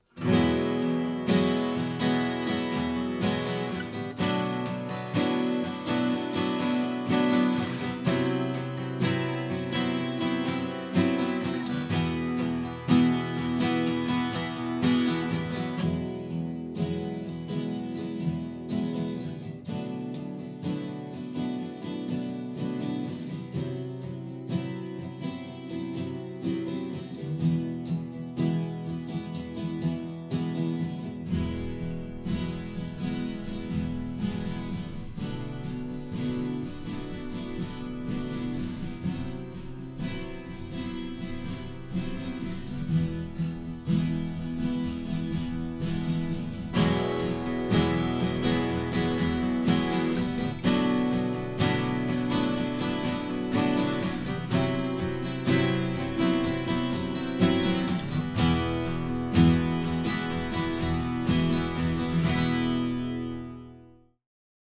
A) It’s a cube made out of Sennheiser MKH 8050s.